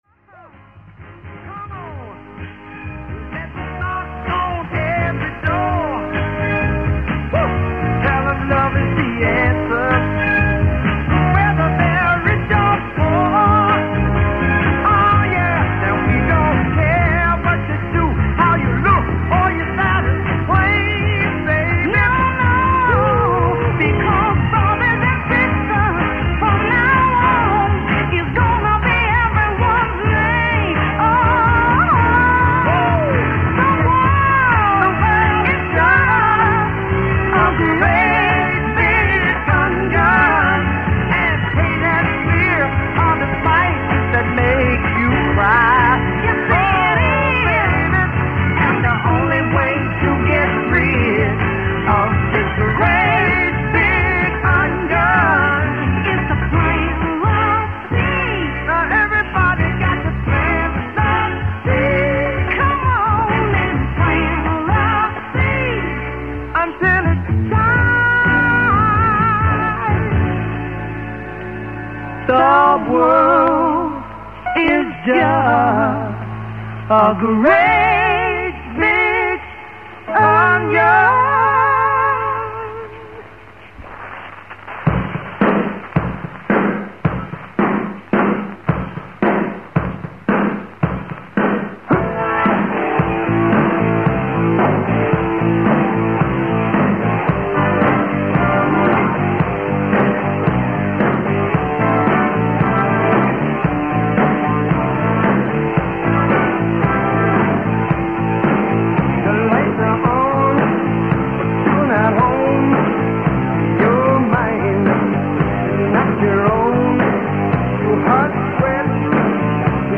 The format moved away from the previous Irish country music diet and was replaced by American country, oldies and some contemporary.
The music consists of pop and chart hits with some oldies.